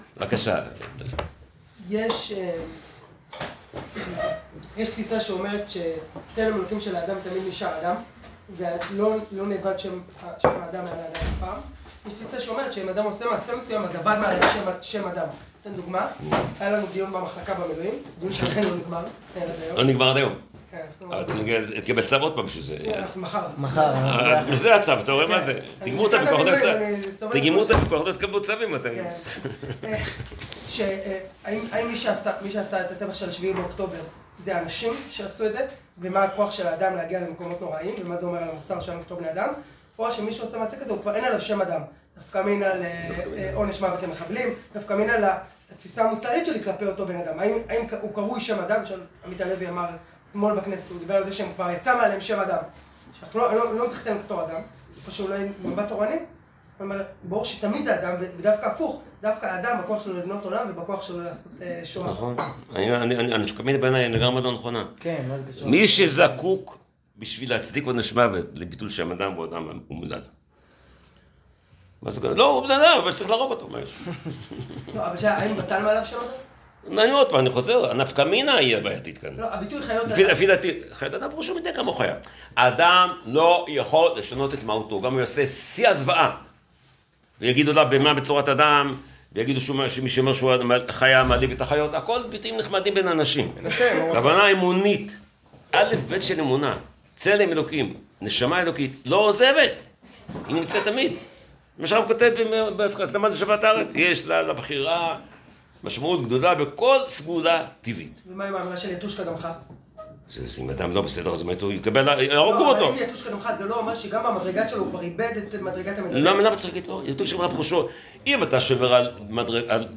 שיעור צלם אנוש